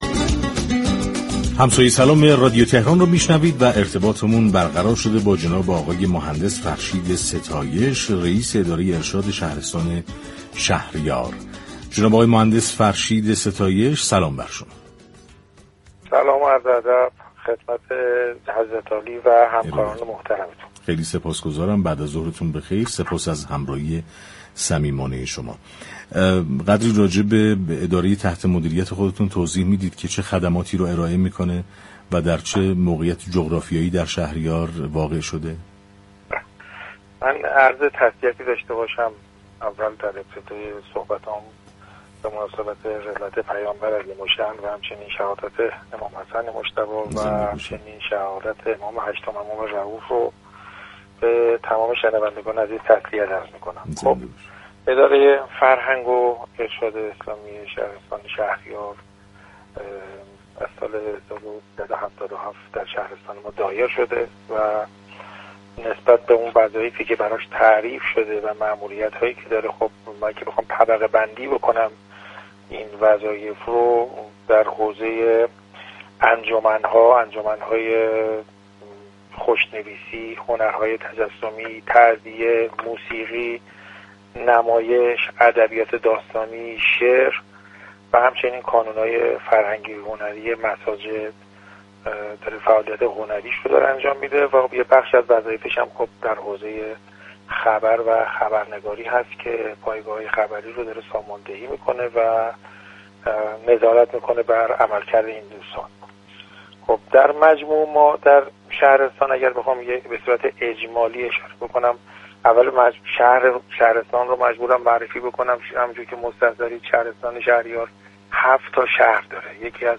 برنامه «همسایه سلام»؛ شنبه تا چهارشنبه ساعت 15 تا 16 از رادیو تهران پخش می‌شود.